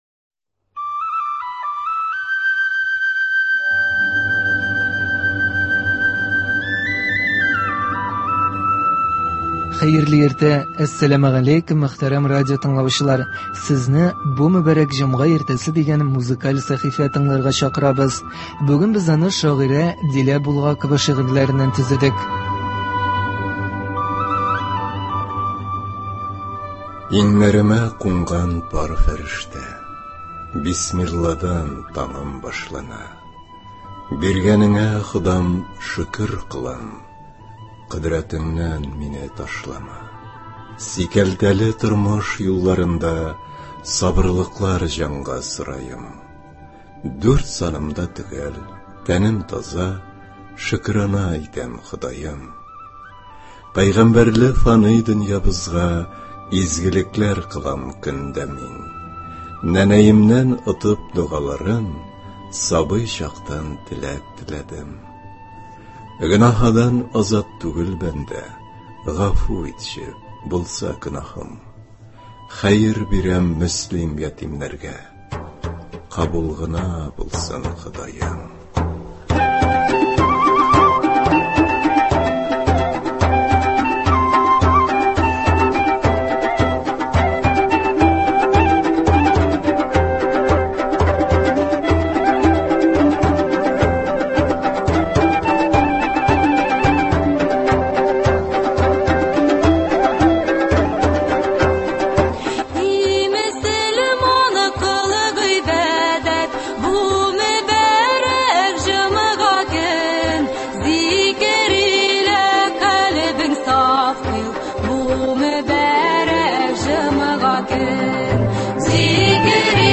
музыкаль сәхифә